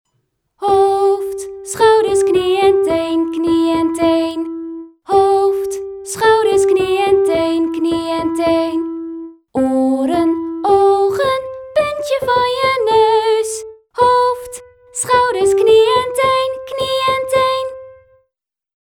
Liedjes
Track-06-Hoofd-schouders-knie-en-teen-zang-en-pianobegeleiding.mp3